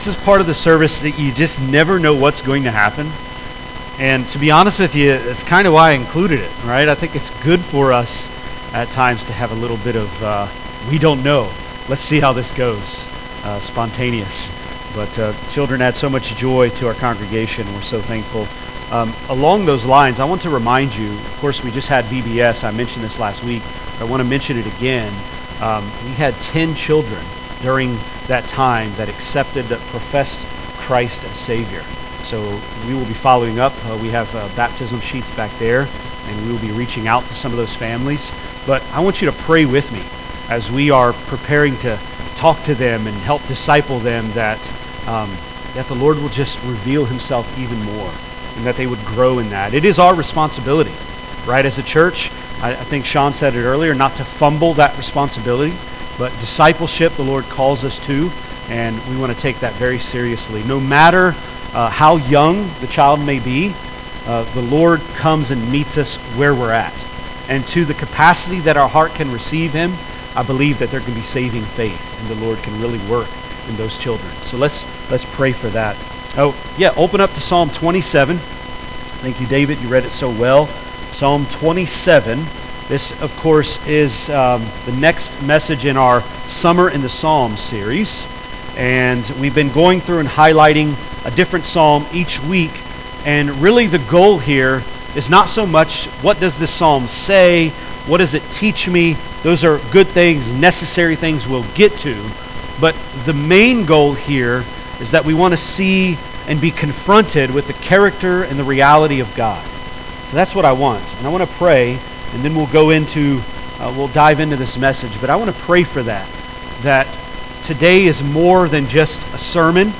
Sermons | Florence Alliance Church